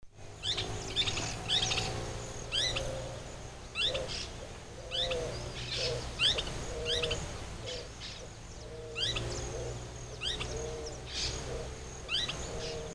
Hornerito Copetón (Furnarius cristatus)
Nombre en inglés: Crested Hornero
Fase de la vida: Adulto
Localidad o área protegida: Reserva privada Don Felix y Sacha Juan
Condición: Silvestre
Certeza: Vocalización Grabada